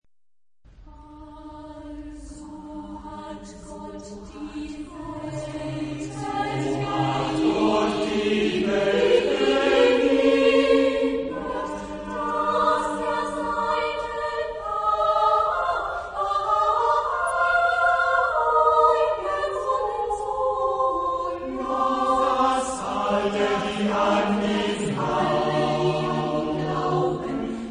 Verlag: Bärenreiter-Verlag [Deutschland] , 1933 ; in Der Jahrkreis - die Sätze für dreistimmigen gemischten Chor (48 S.)
Genre-Stil-Form: geistlich ; Choral ; zeitgenössisch
Chorgattung: SAM  (3 gemischter Chor Stimmen )